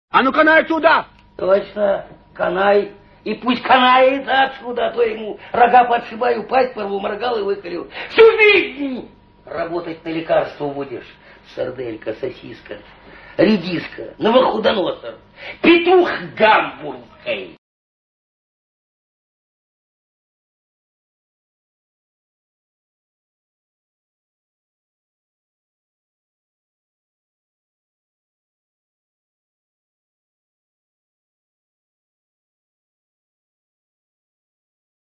Звуки цитат из фильмов